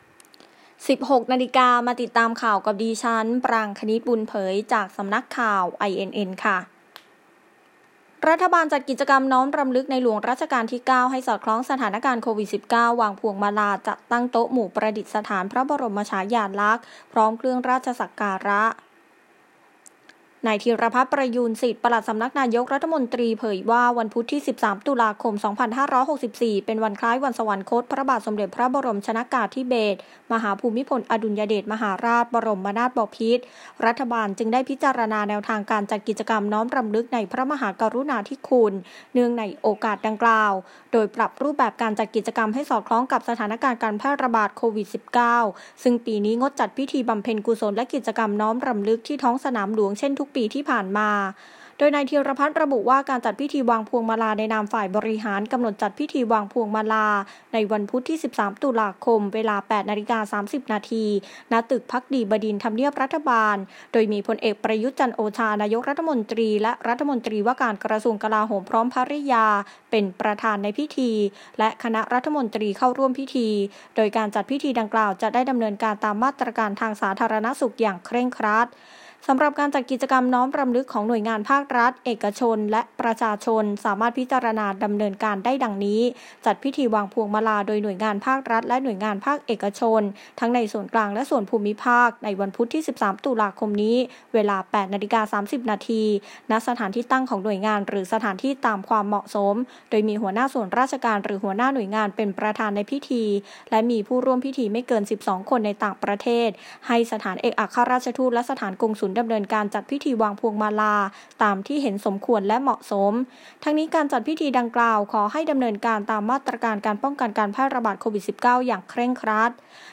ข่าวต้นชั่วโมง 16.00 น.